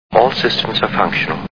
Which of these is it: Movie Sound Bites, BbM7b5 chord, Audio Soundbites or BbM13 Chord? Movie Sound Bites